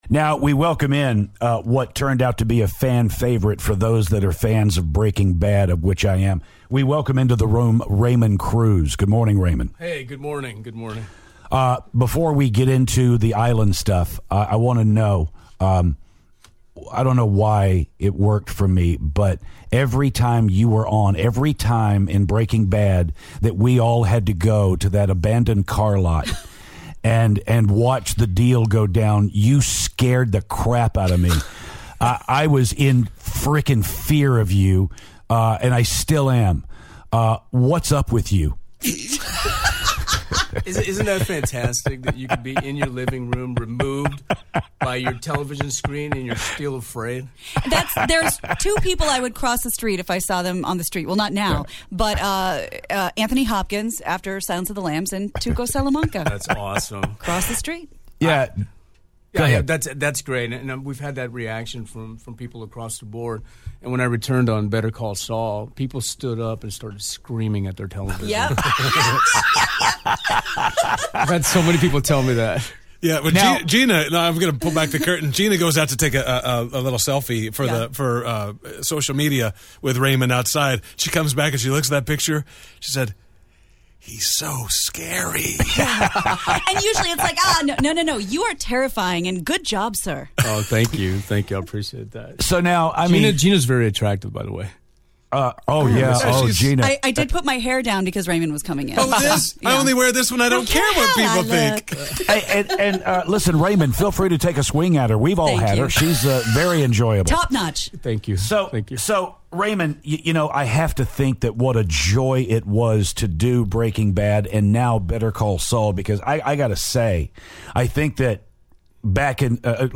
Raymond Cruz: Full Interview